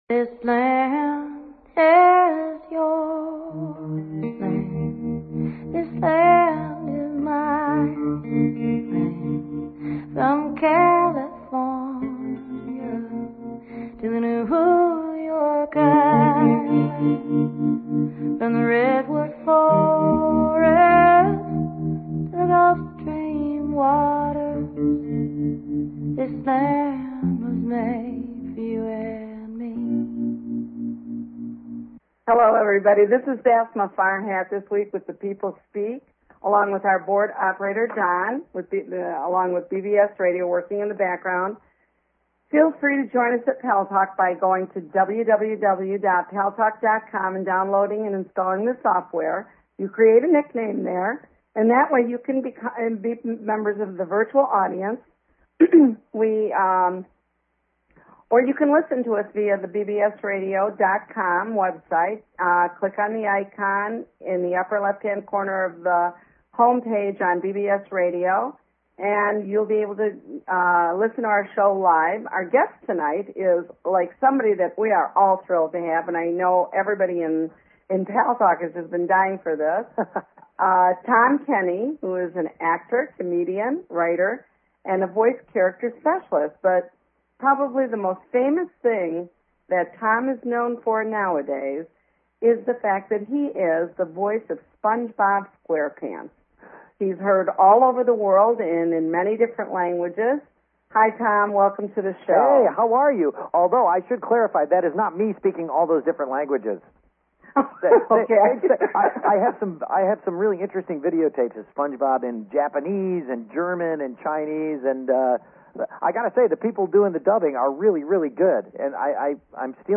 The People Speak with guest, Tom Kenny